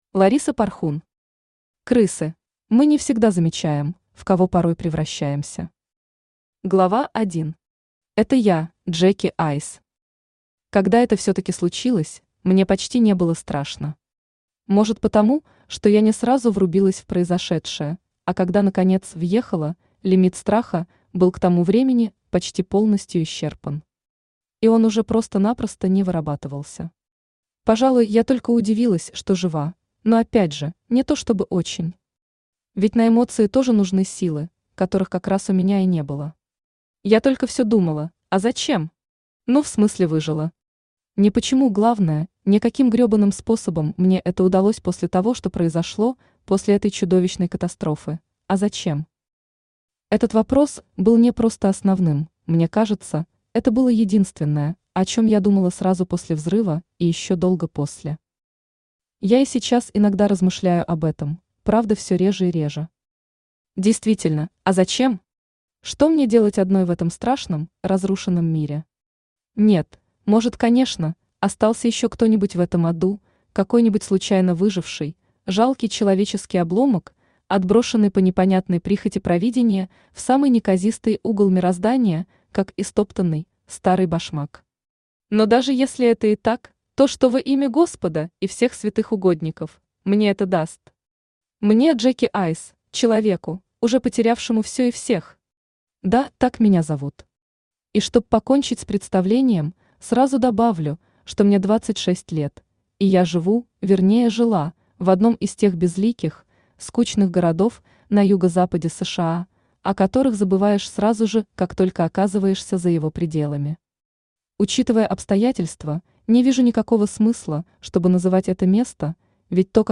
Аудиокнига Крысы | Библиотека аудиокниг